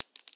bulletout_2.mp3